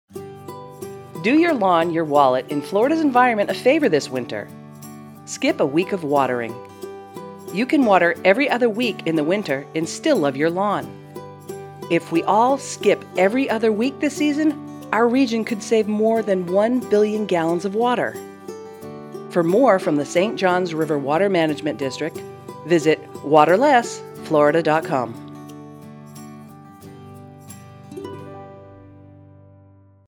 Public service announcements
Skip a Week--PSA 2 30sec.mp3